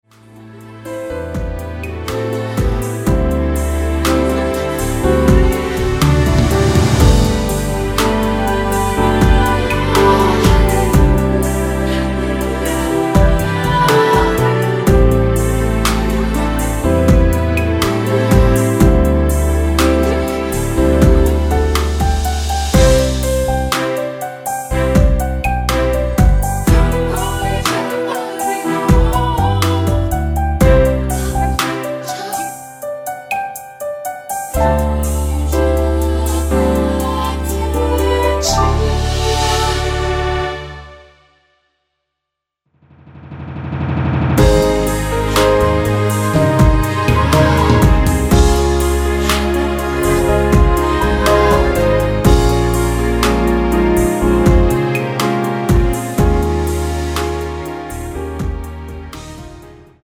내린 코러스 포함된 MR 입니다.
원곡의 보컬 목소리를 MR에 약하게 넣어서 제작한 MR이며